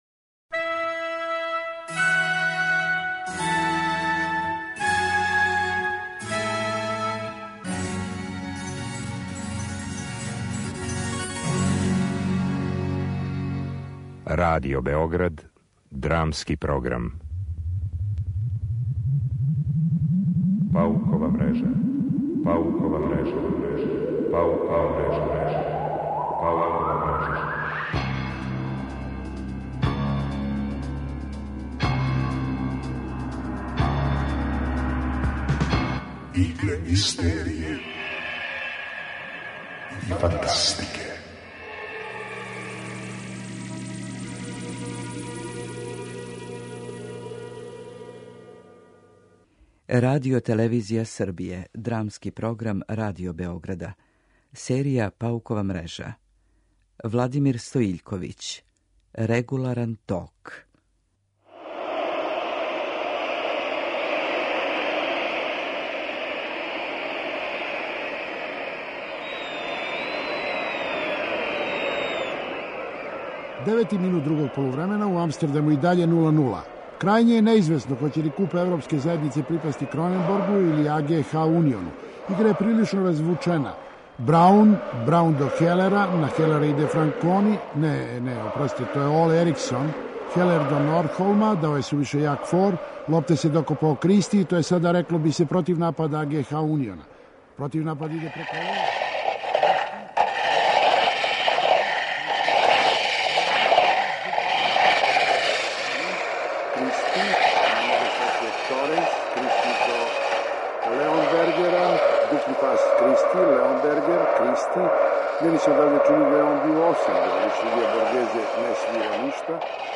Драмски програм: Паукова мрежа